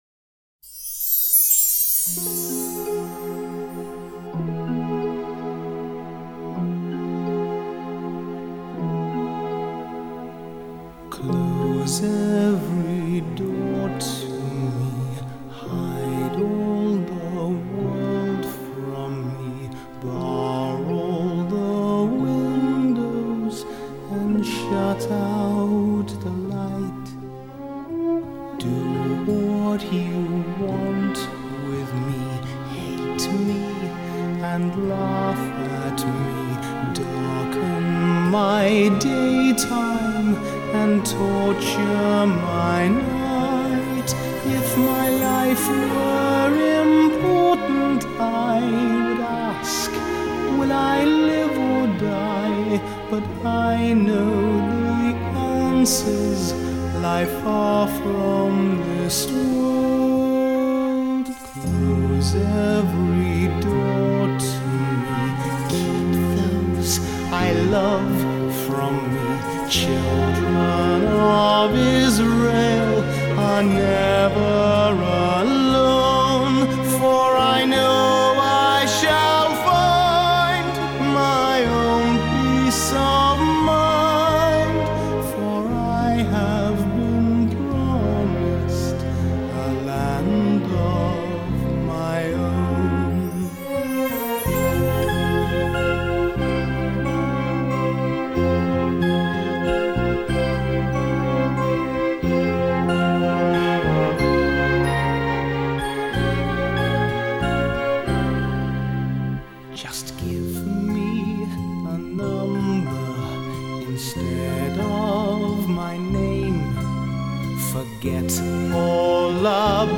明朗澄澈的录音效果